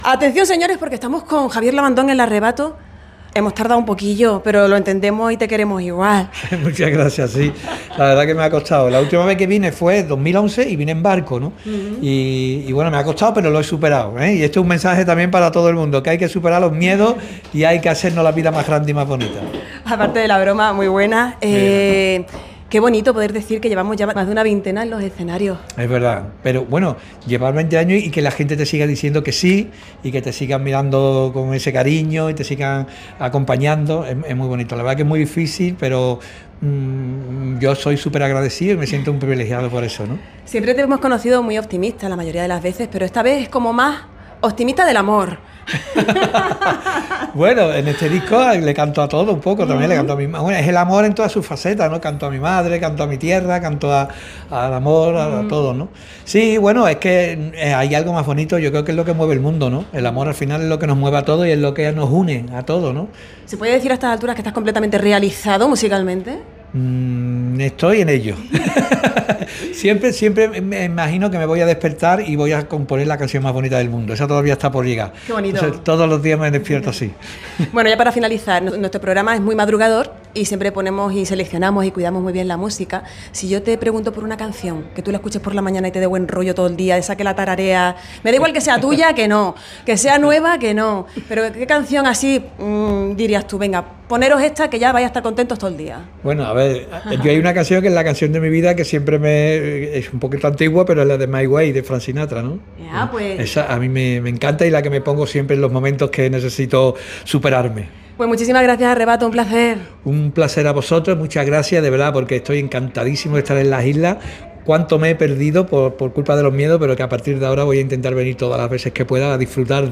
Entrevista-en-Radio-Insular-Fuerteventura-con-El-Arrebato.mp3